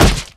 c:\program files (x86)\op-2.2\gamedata\sounds\monsters\flesh\flesh_attack_hit_0.ogg
flesh_attack_hit_0.ogg